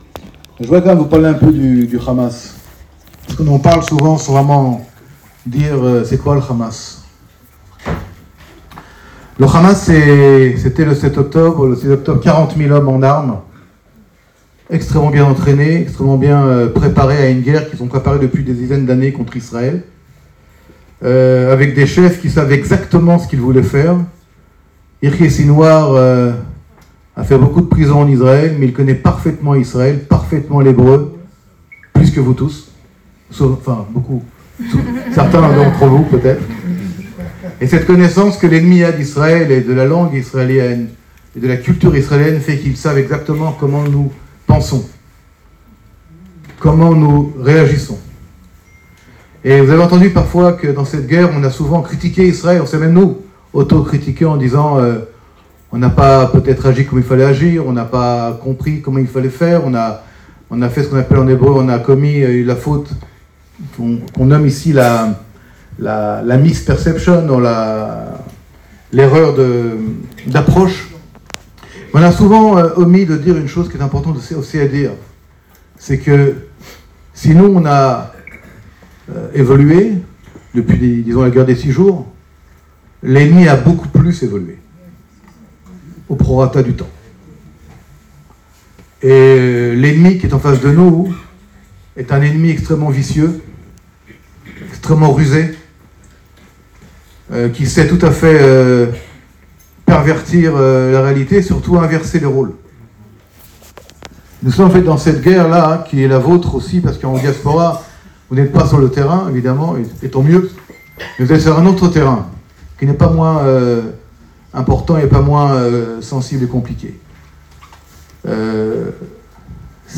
Discours d'Olivier Rafowicz lors du lancement de DDF Israel (24/05/24)